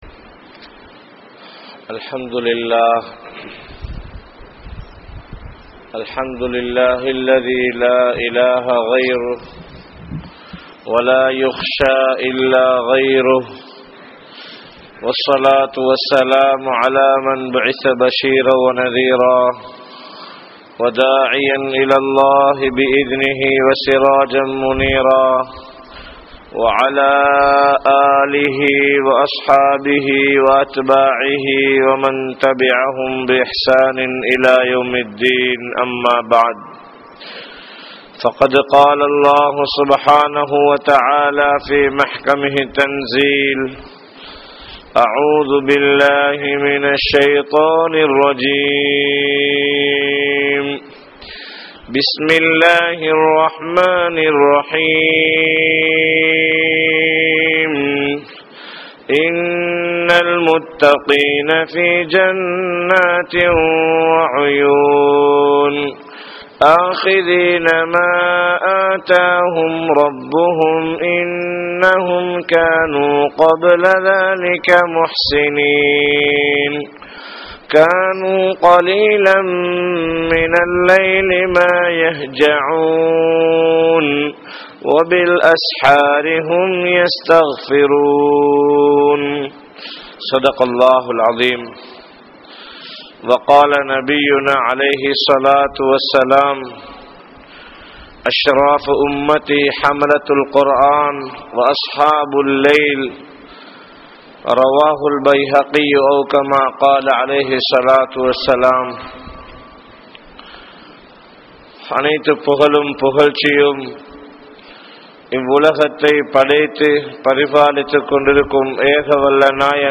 Thahajjuth Tholuhaiyai Tholum Manitharhal (தஹஜ்ஜூத் தொழுகையை தொழும் மனிதர்கள்) | Audio Bayans | All Ceylon Muslim Youth Community | Addalaichenai